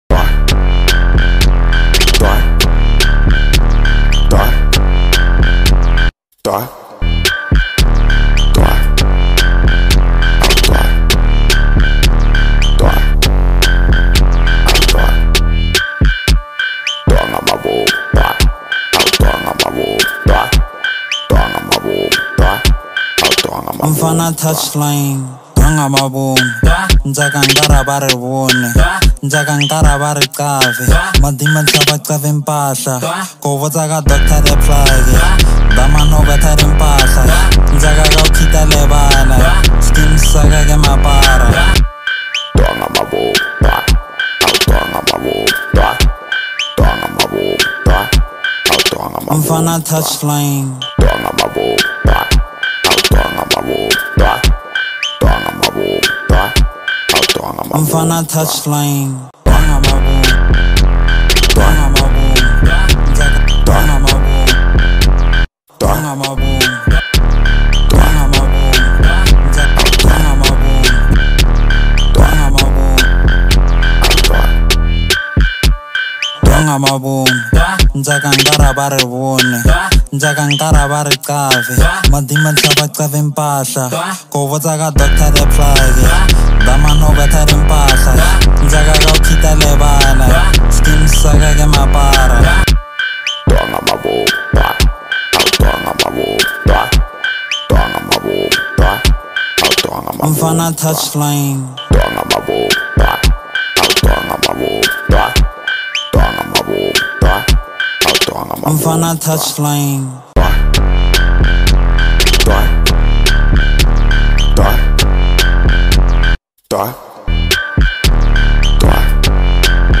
piano track
amapiano song